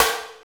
Index of /90_sSampleCDs/Roland - Rhythm Section/KIT_Drum Kits 8/KIT_Jack Swing